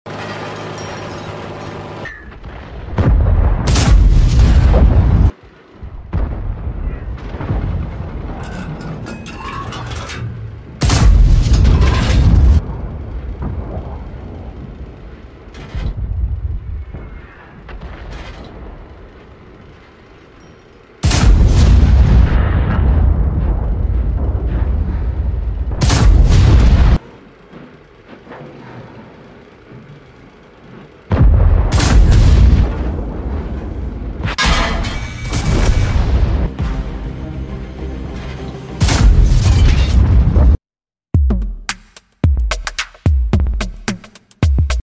World of Tanks FV400F SCRAPING sound effects free download